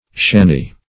Shanny \Shan"ny\, n.; pl. Shannies. [Etymol. uncertain.]